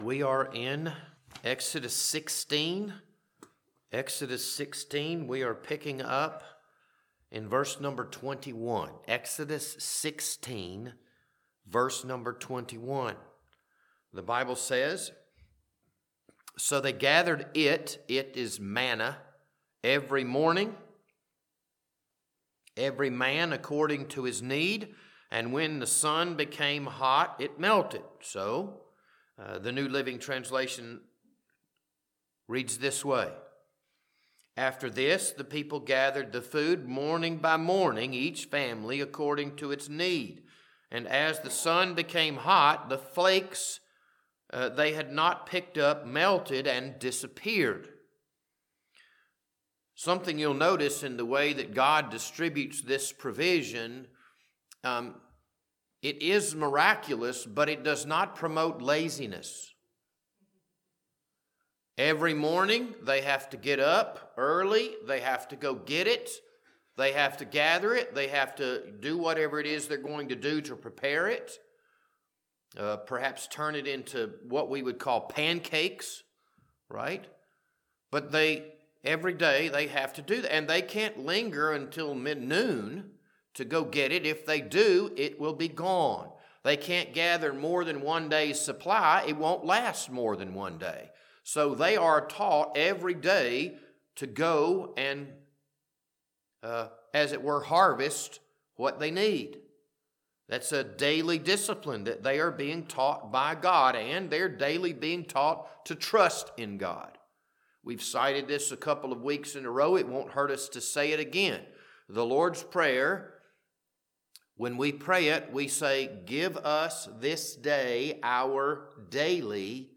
This Wednesday evening Bible study was recorded on March 4th, 2026.